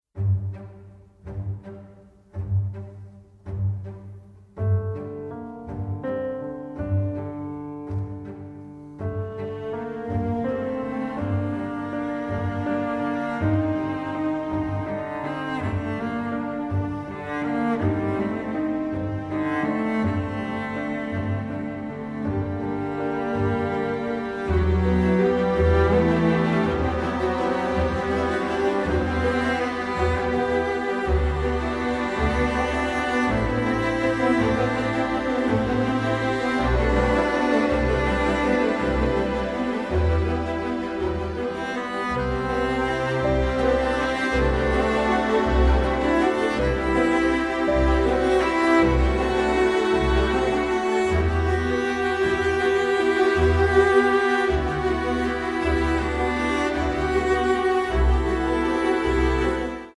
a harrowing, incredibly emotive score
Recorded at Abbey Road Studios